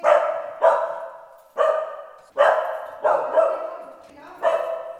dog-dataset / puppy /puppy_0010.wav
puppy_0010.wav